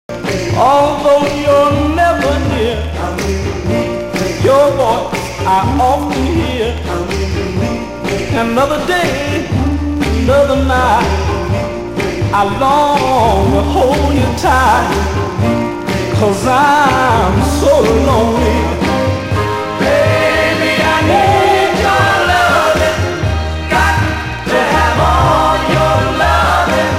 心地良く、適度にメリハリのあるビートに流麗な伴奏とジェントルな歌唱が乗った名録音。
(税込￥1320)   SOUL / R&B